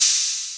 Open Hat (5).wav